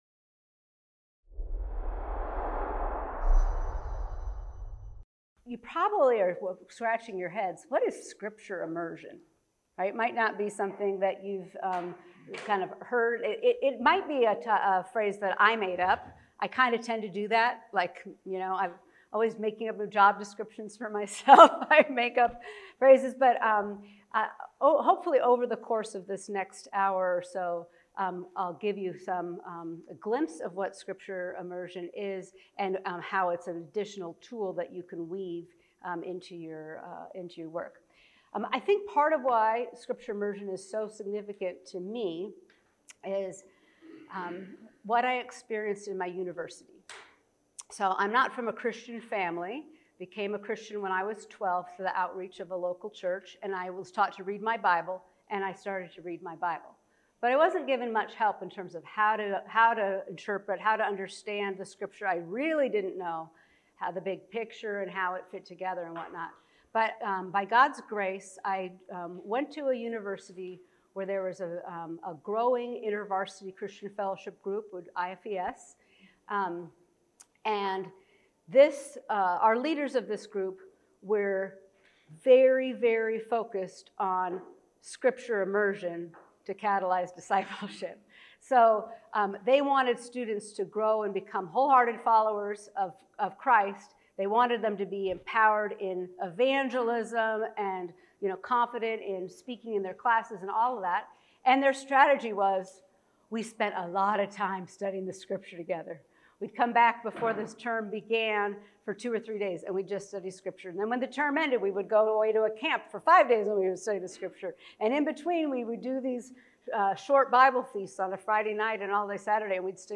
Scripture is central to discipleship, yet common forms of Scripture engagement (such as personal Bible reading and expository preaching) do not necessarily result in spiritual maturity. This talk explores how periodic Scripture immersion experiences can shift peoples’ posture towards Scripture and help them to be doers of the Word and not merely hearers.